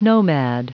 Prononciation du mot nomad en anglais (fichier audio)
Prononciation du mot : nomad